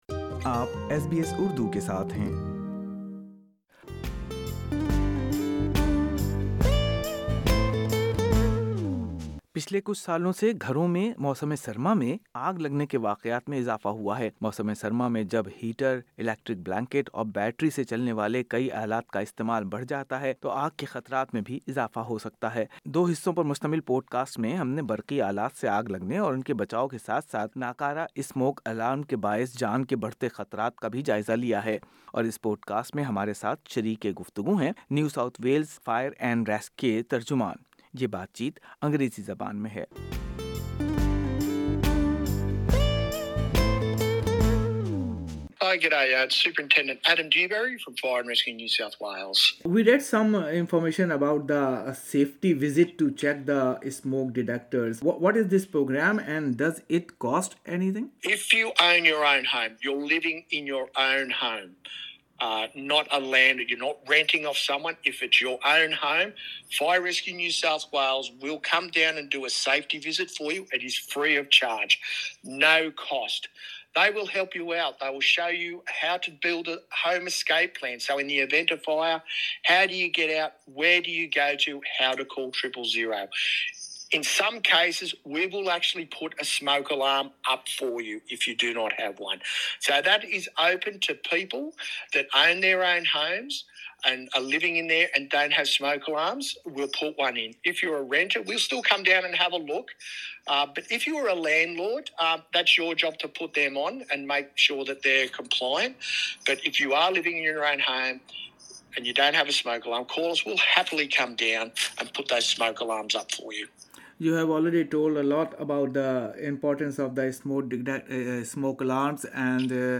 بات چیت